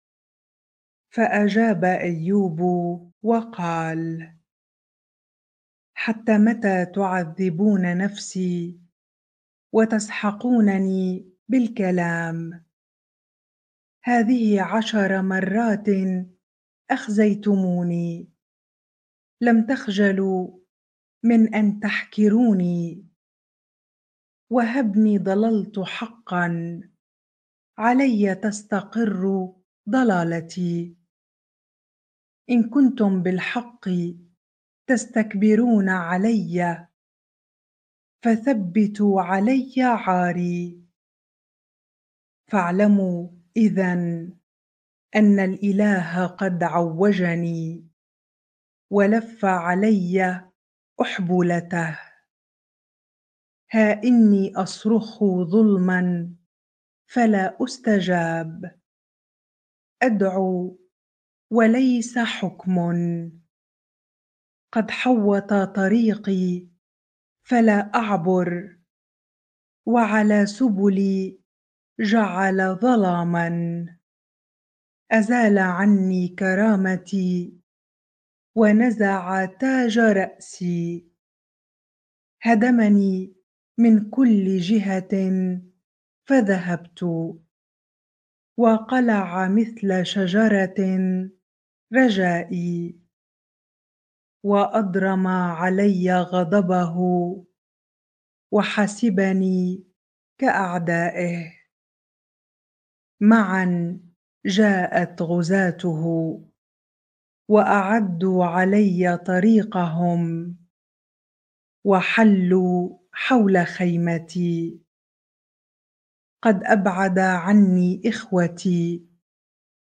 bible-reading-Job 19 ar